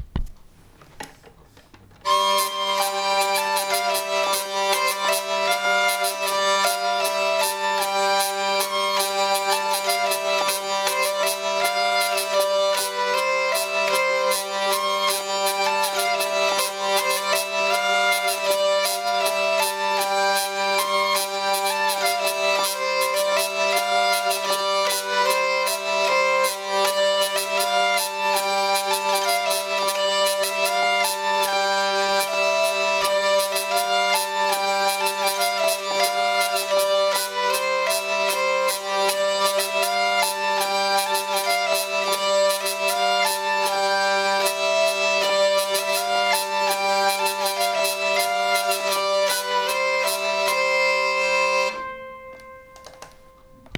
Drehleier Zusammenspiel
Wir sollten es zweistimmig spielen können. C-Dur PDF
Schottish_2_Stimmen_1.WAV